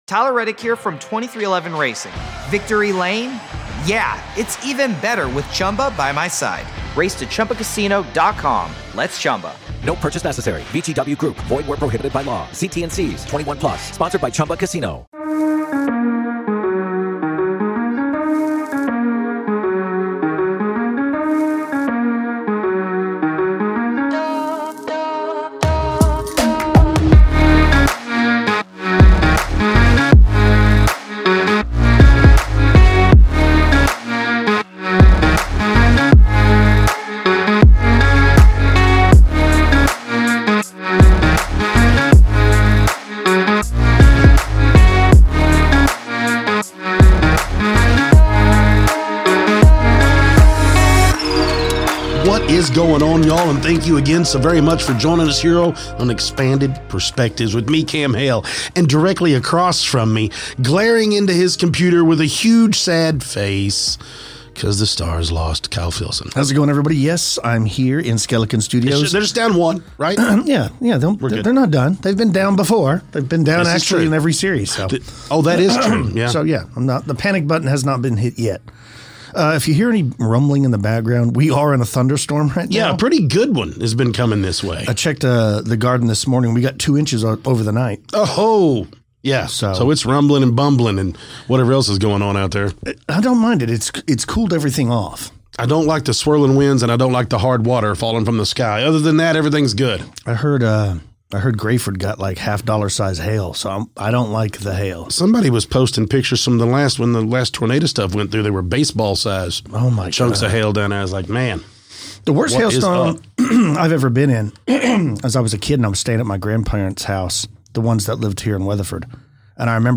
In this episode of Expanded Perspectives, the guys kick things off by chatting about the recent thunderstorms in the area, but a sudden lightning strike disrupts the recording